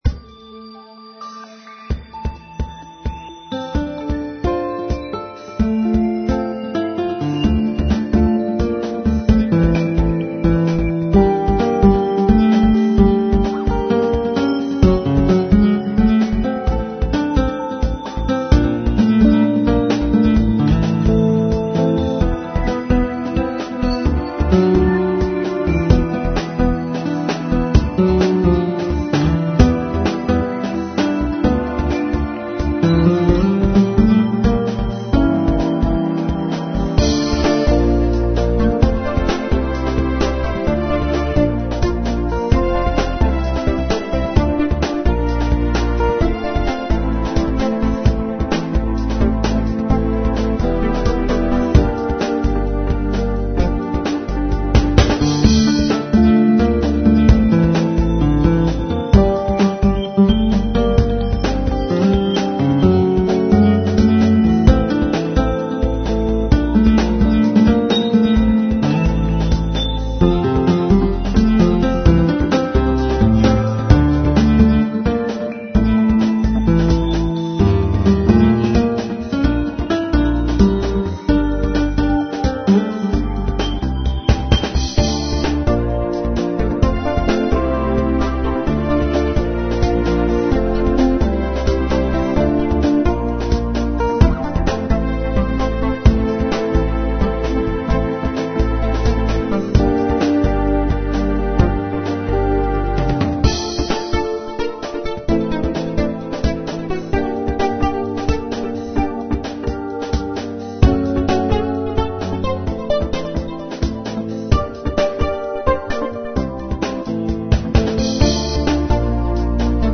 Dramatic Instrumental Electro-Pop with Classic Guitar lead